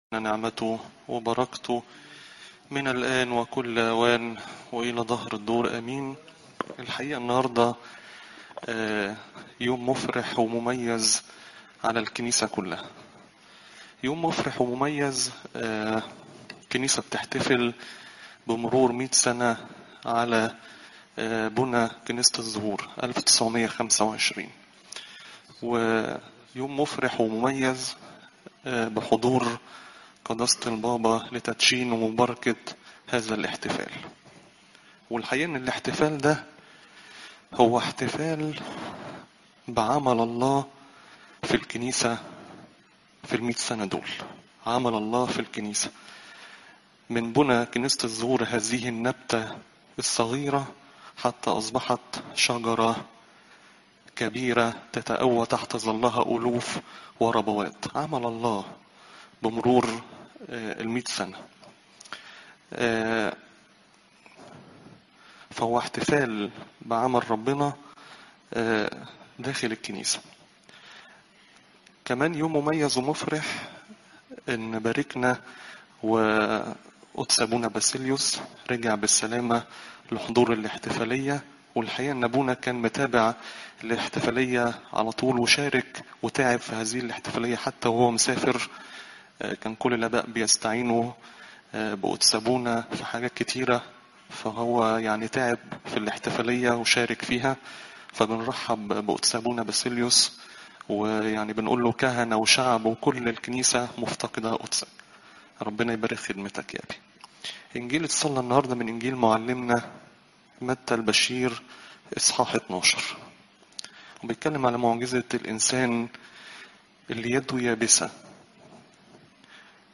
عظات قداسات الكنيسة (لو 16 : 1 - 12)